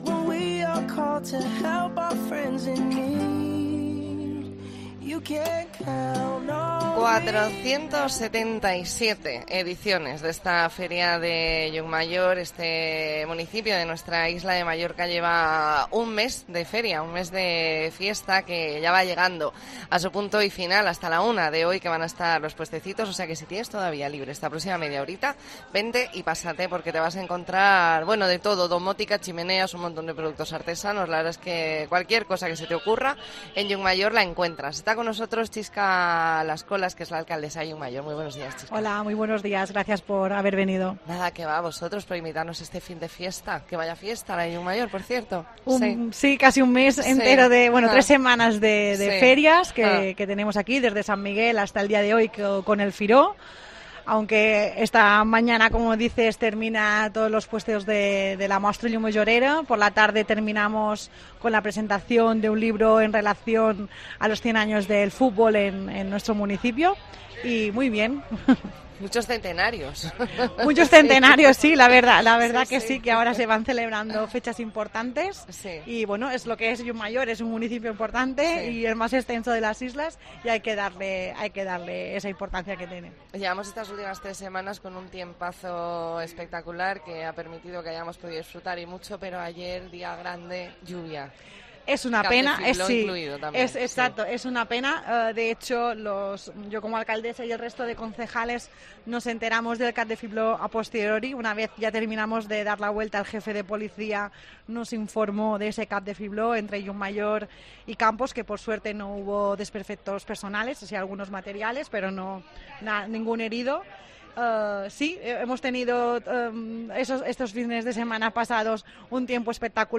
AUDIO: Especial 'La Mañana en Baleares' desde Es Firó de Llucmajor
Redacción digital Madrid - Publicado el 16 oct 2023, 12:30 - Actualizado 16 oct 2023, 17:18 1 min lectura Descargar Facebook Twitter Whatsapp Telegram Enviar por email Copiar enlace Hablamos con Xisca Lascolas Rosselló, alcaldesa de Llucmajor . Entrevista en La Mañana en COPE Más Mallorca, lunes 16 de octubre de 2023.